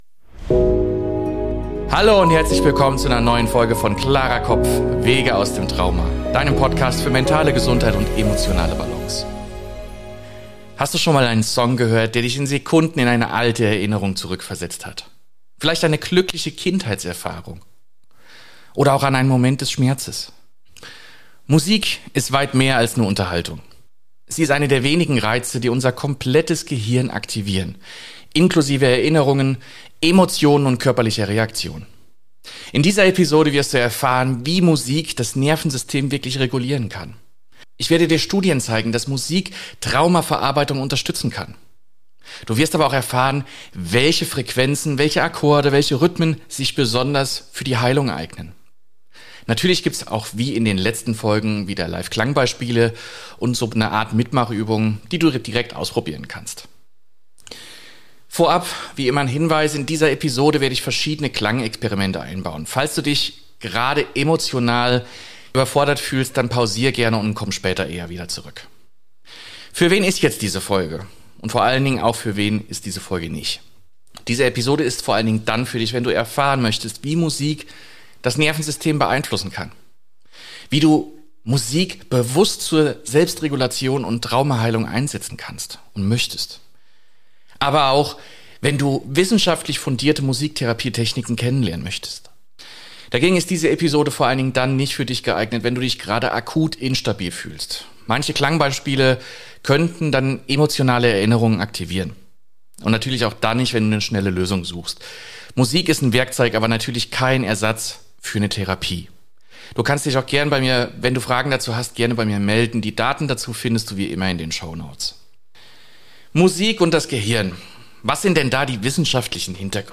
Diese Episode ist wissenschaftlich fundiert, bietet aber auch direkte Klangbeispiele, mit denen du selbst testen kannst, wie Musik auf dich wirkt. Lass uns gemeinsam erkunden, wie Rhythmus, Frequenz und Melodie das Nervensystem regulieren können.